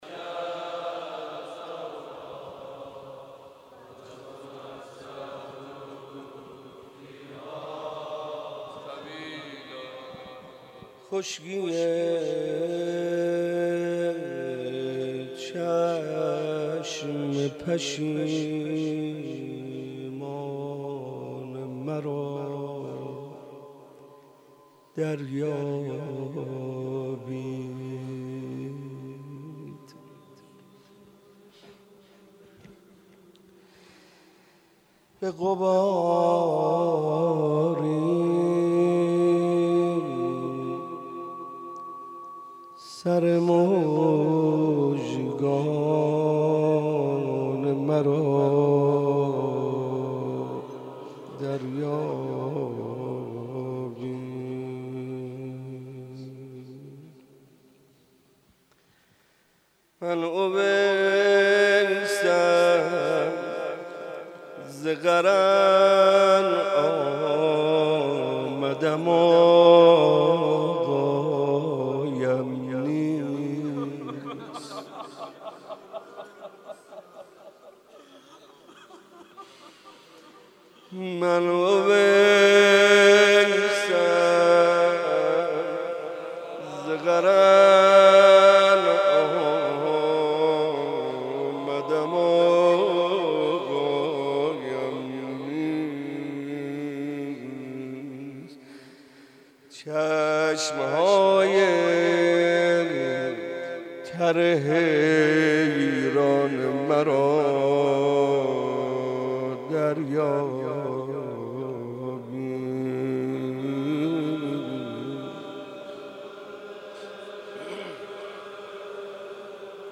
مناسبت : شب سوم محرم
قالب : روضه